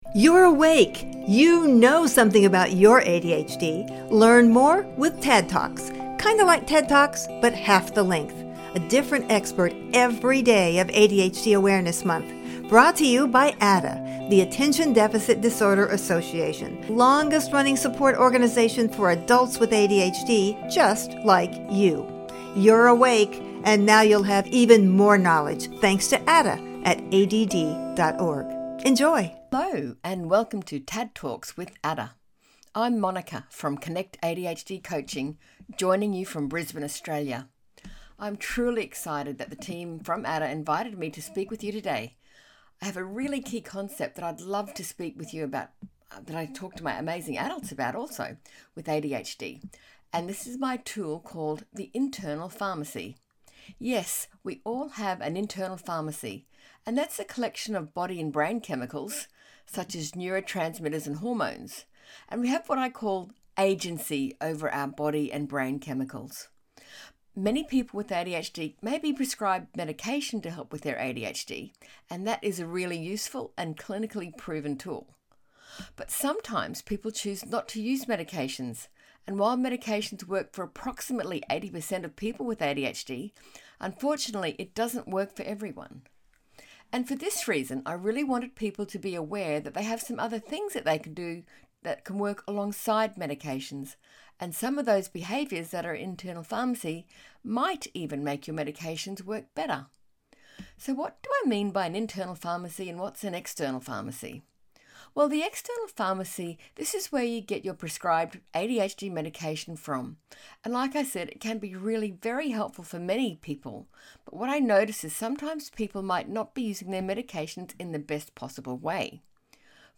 TADD TALK